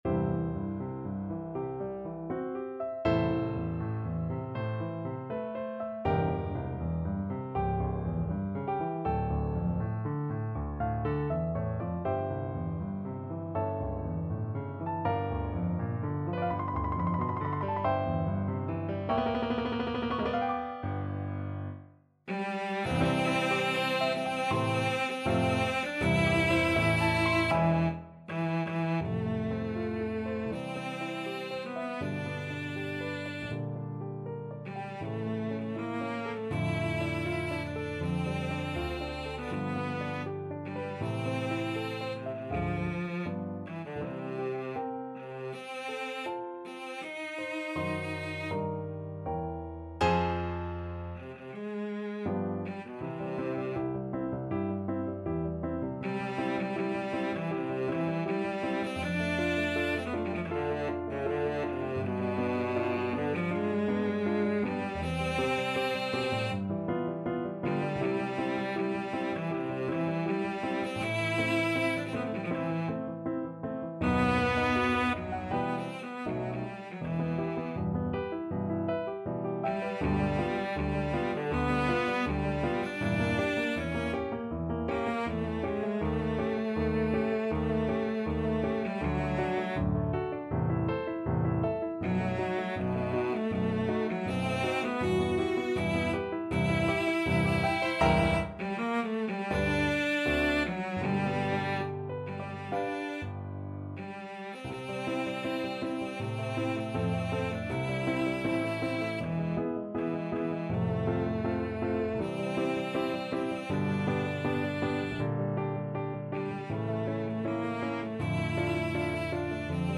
Cello version
G3-A5
4/4 (View more 4/4 Music)
Classical (View more Classical Cello Music)